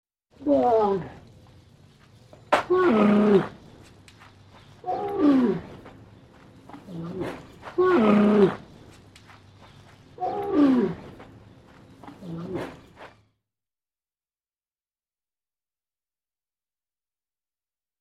Фырканье верблюда
Тут вы можете прослушать онлайн и скачать бесплатно аудио запись из категории «Животные, звери».